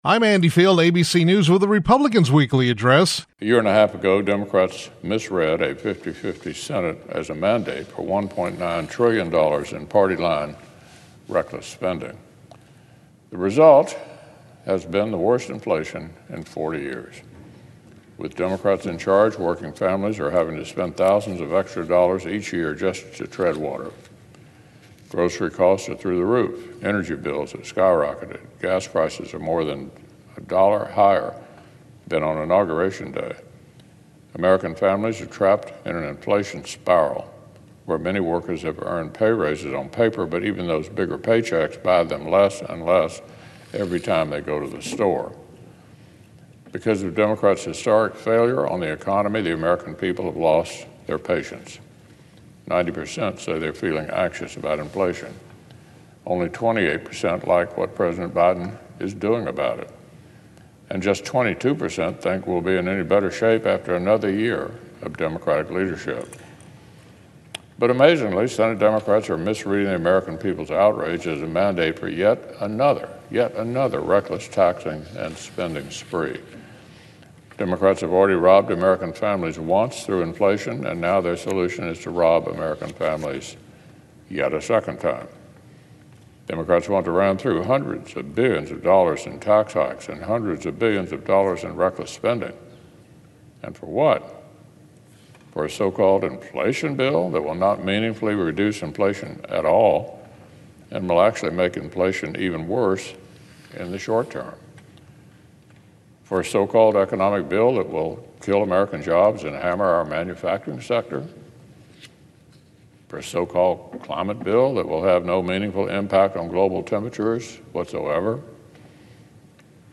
U.S. Senate Republican Leader Mitch McConnell (R-KY) delivered remarks recently on the Senate floor regarding Democrats’ Reckless Tax and Spending Spree.